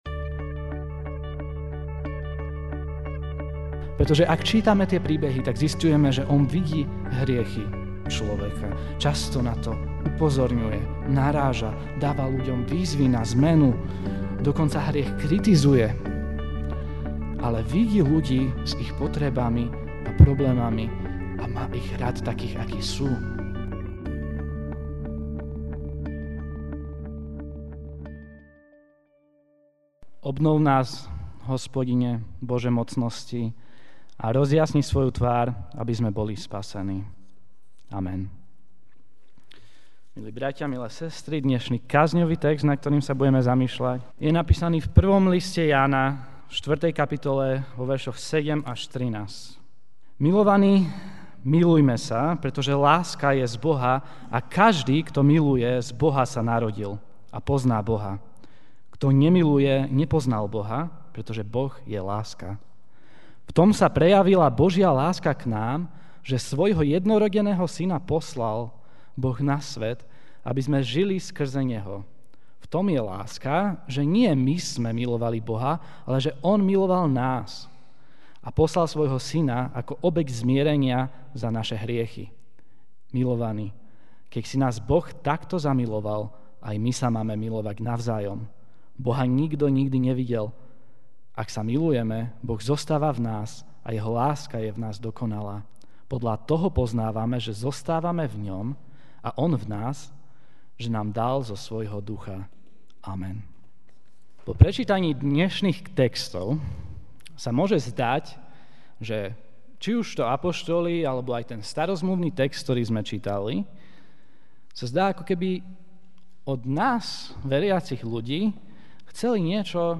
Ranná kázeň: Môj blížny (1.